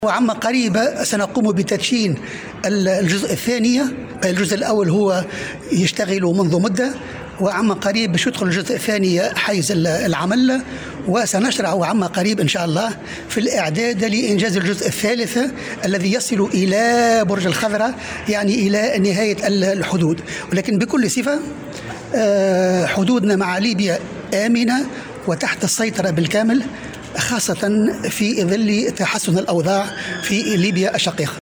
وأضاف في تصريح اليوم لمراسل "الجوهرة أف أم" على هامش إختتام التمرين البحري "فونيكس إكسبريس21"، أن الحدود التونسية آمنة وتحت السيطرة، خاصة في ظل تحسن الأوضاع الأمنية في ليبيا.